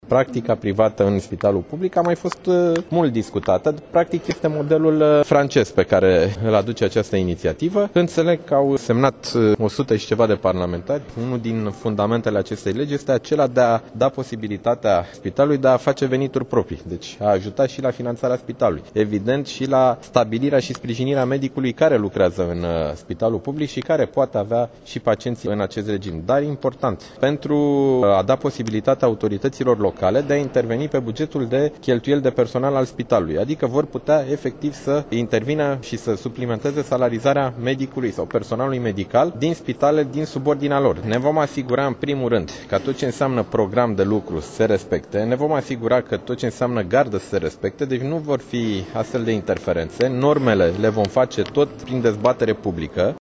Ministrul Sănătăţii, Nicolae Bănicioiu: